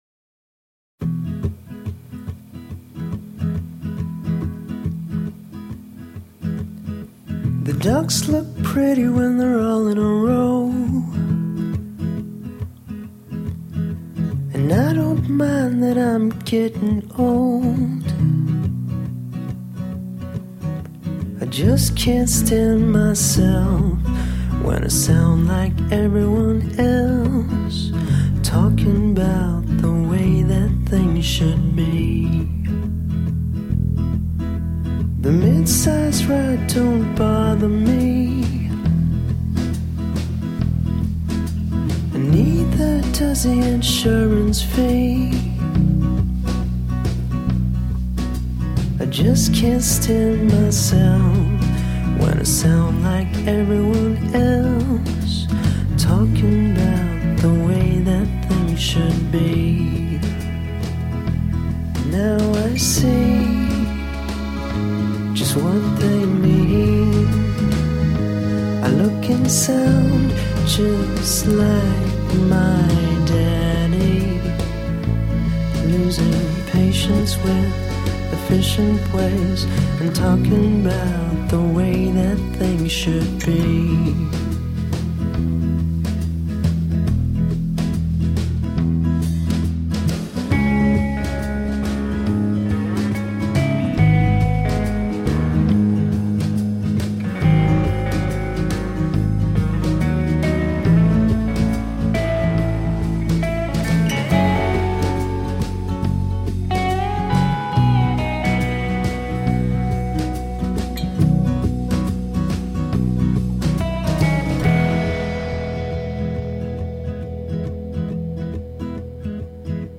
Catchy, whip smart alt-rock.
which isn't unexpected given it was a "bedroom recording".
a pretty (and melancholy) number that recalls Jon Brion
Absolute Powerpop
Tagged as: Alt Rock, Other, Indie Rock